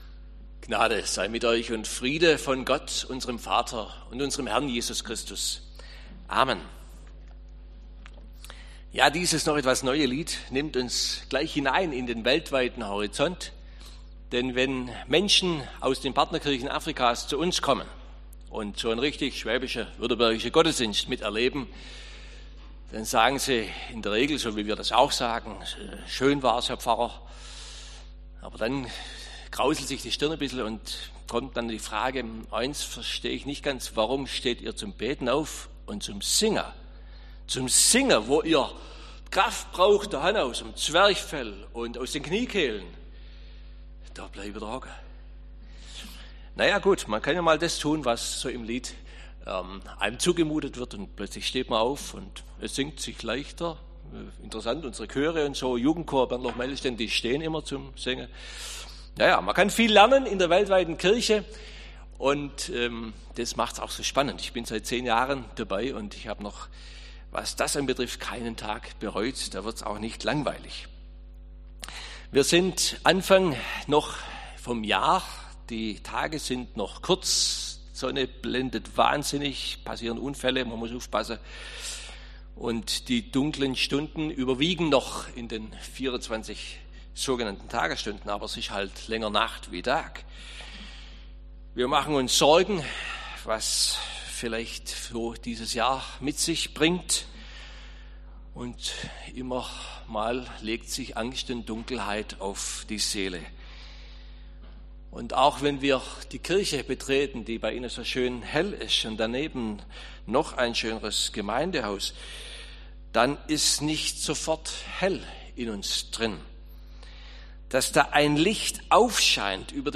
Predigt
am 3. Sonntag vor der Passionszeit. Predigttext: Epheser 3,1-7